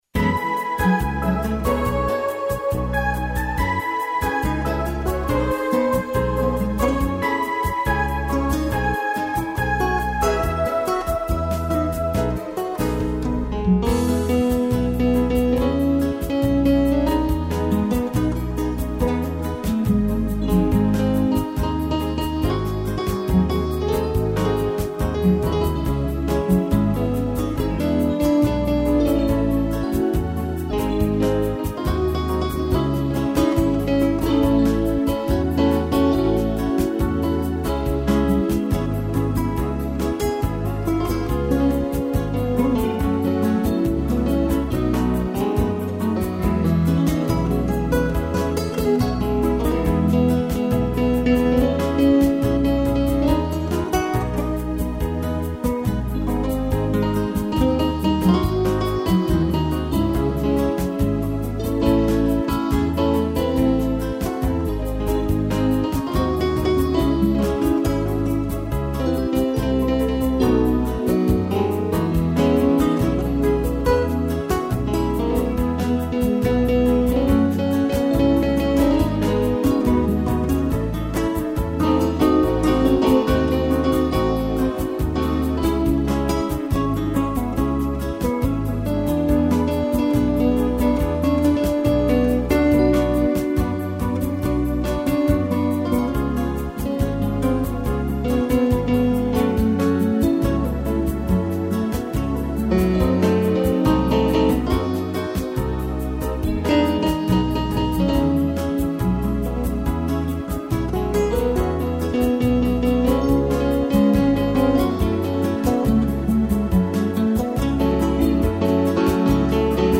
violão